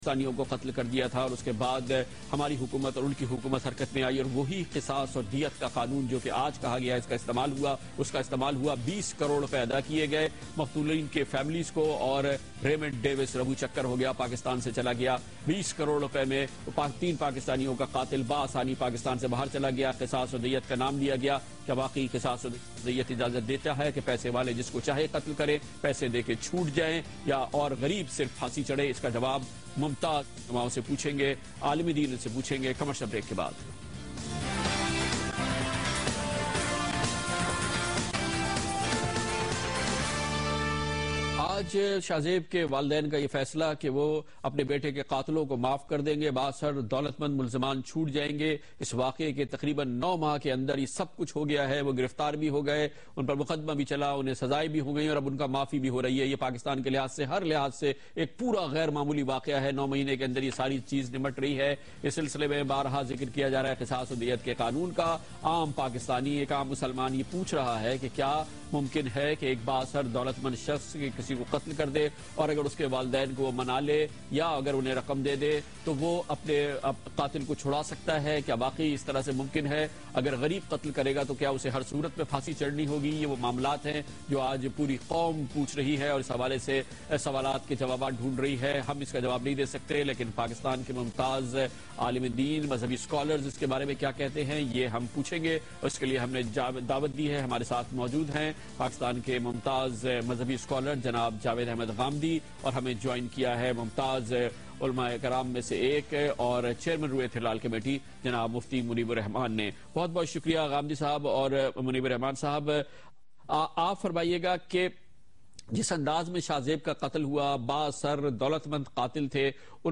Javed Ahmad Ghamidi and Mufti Muneeb discussing